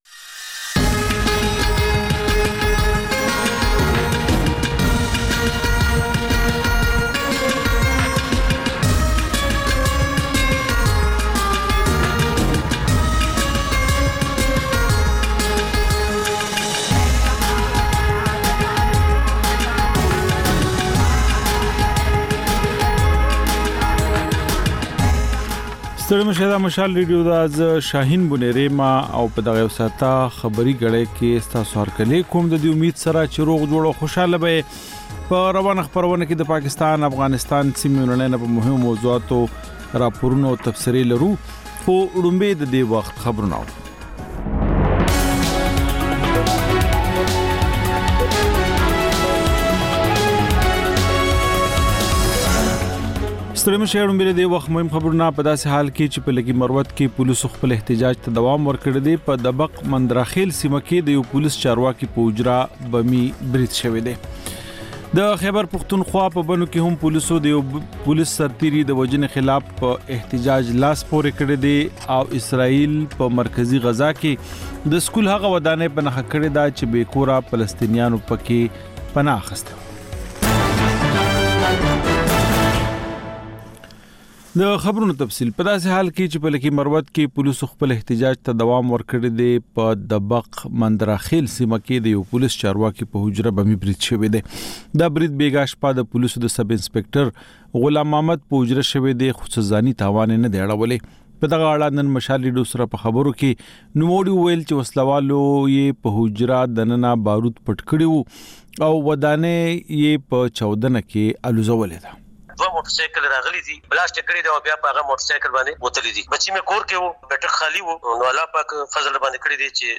دلته د مشال راډیو د ۱۴ ساعته خپرونو دویمه او وروستۍ خبري ګړۍ تکرار اورئ. په دې خپرونه کې تر خبرونو وروسته بېلا بېل سیمه ییز او نړیوال رپورټونه، شننې، مرکې، کلتوري او ټولنیز رپورټونه خپرېږي.